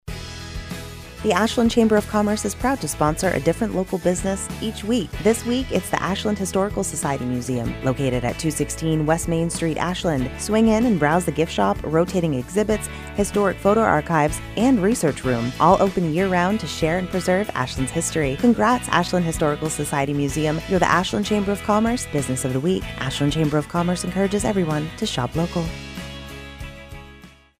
Each week the Ashland Chamber of Commerce highlights a business on Heartland Communications radio station WATW 1400 AM. The Chamber draws a name from our membership and the radio station writes a 30-second ad exclusively for that business.